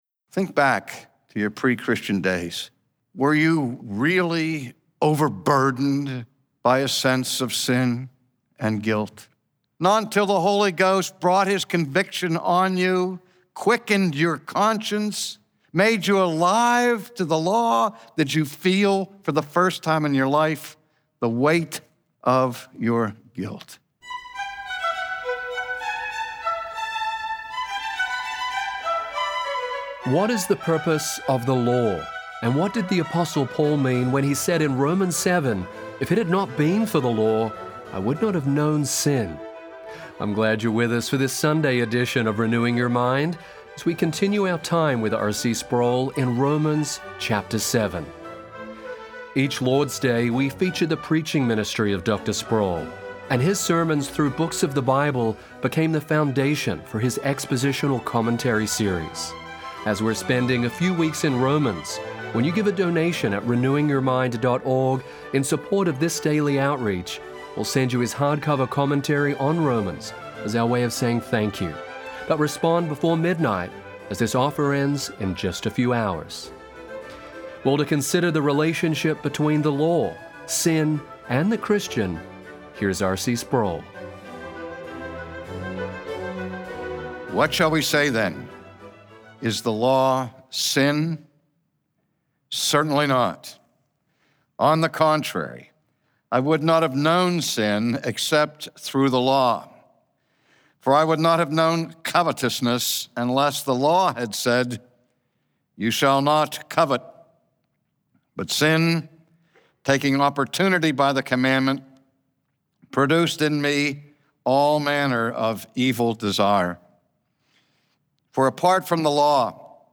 Sin can offer momentarily pleasure, but it will never bring genuine and lasting happiness. From his sermon series in Romans